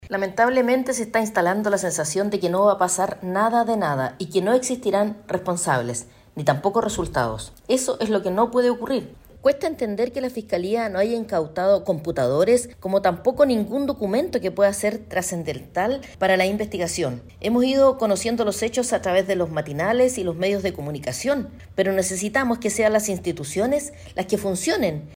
De allí el cuestionamiento de la diputada UDI, Flor Weisse, querellante por las millonarias transferencias desde Vivienda a la Fundación Urbanismo Social, quien lamentó que el detalle de los casos se conozca a través de la prensa y no desde la Fiscalía.